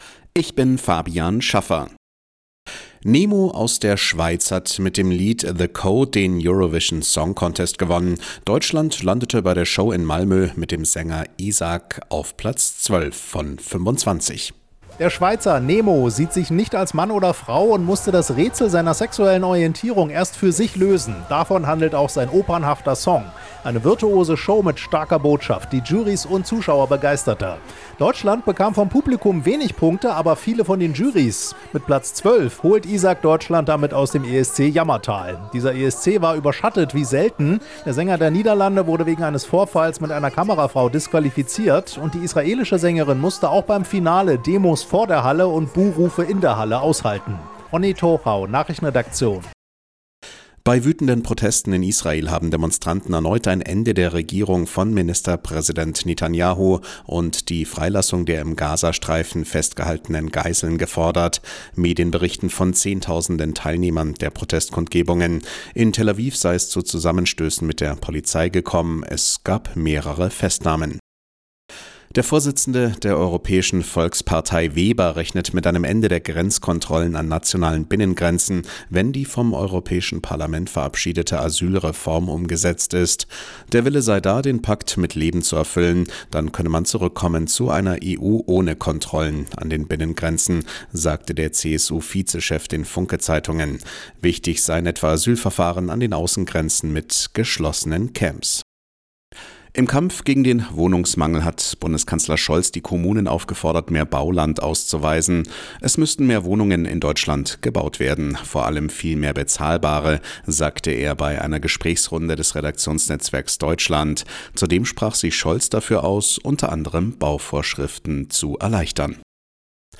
Die Arabella Nachrichten vom Sonntag, 12.05.2024 um 07:06 Uhr - 12.05.2024